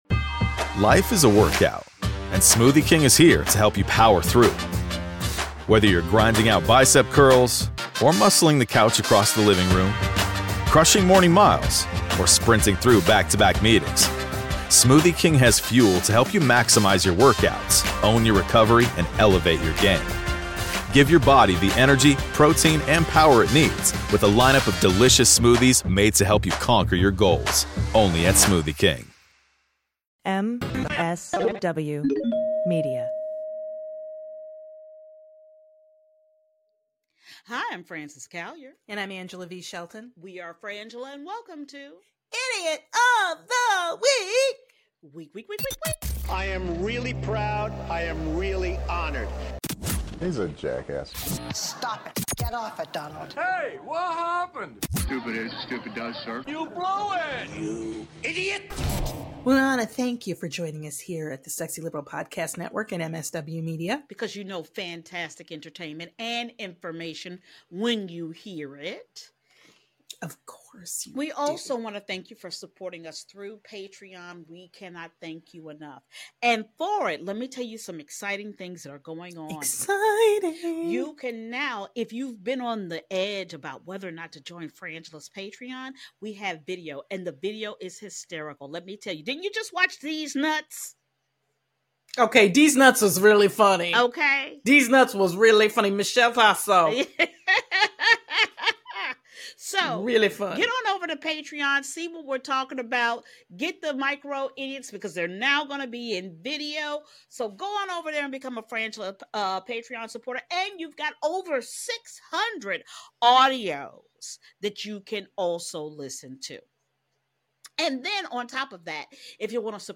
Society & Culture, News, Comedy